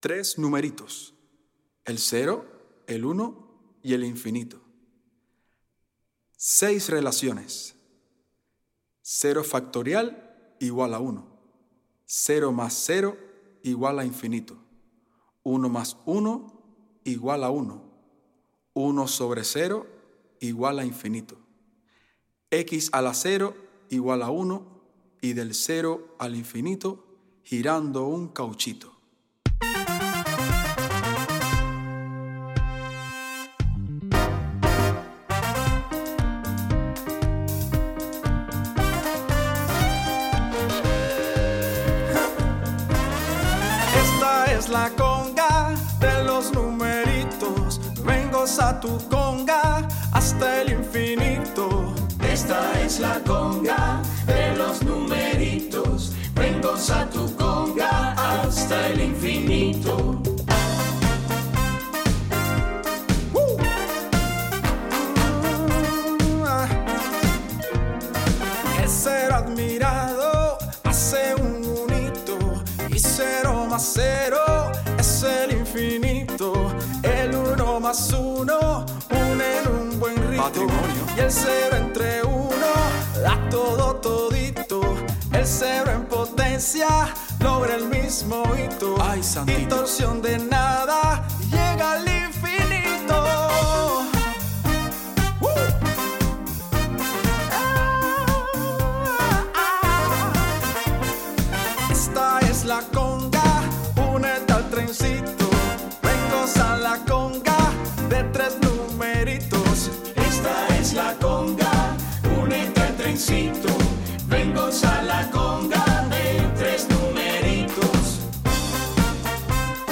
Esta campanita se basa en curiosas relaciones entre los números 0, 1 e , las cuales proveen, a ritmo de conga, una fiel invitación a nuestra santidad, unidad y buena eternidad.
La composición alegre y bailable a continuación se basa en seis relaciones que permiten apreciar algunos aspectos curiosos y certeros de la dinámica del misterio Trinitario: